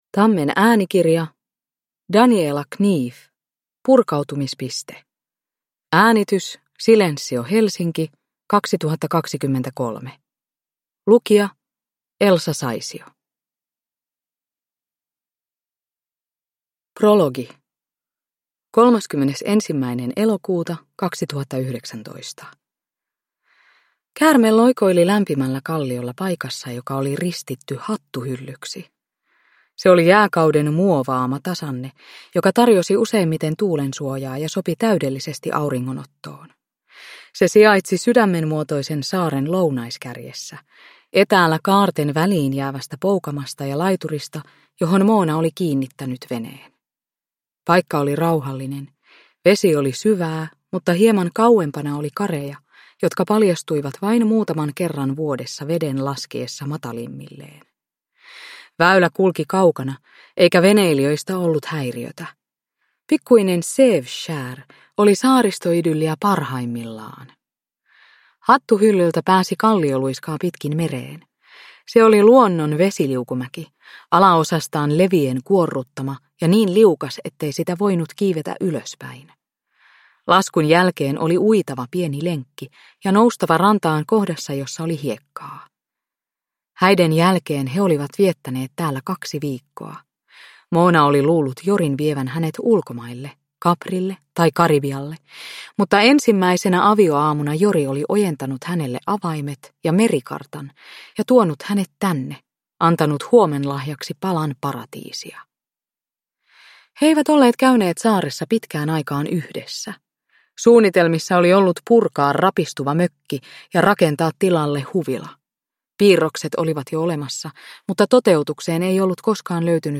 Nedladdningsbar Ljudbok
Ljudbok
Elsa Saisio